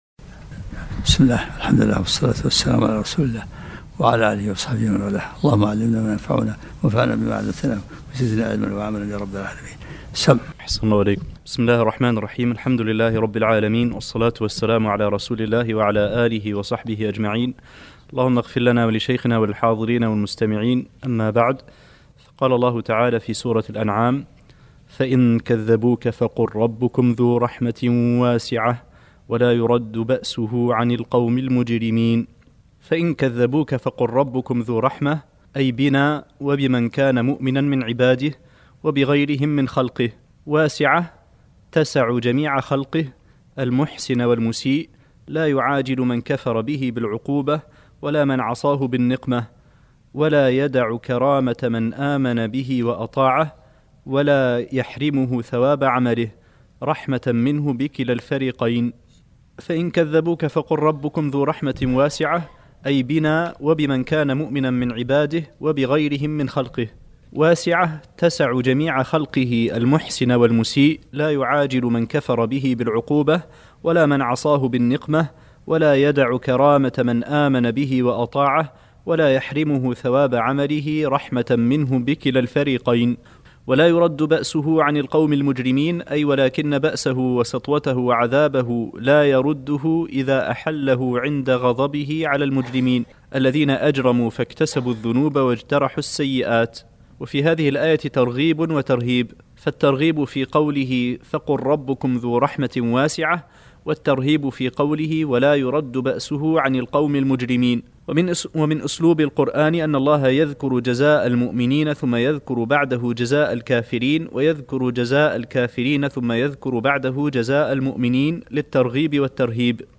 الدرس التاسع و العشرون من سورة الانعام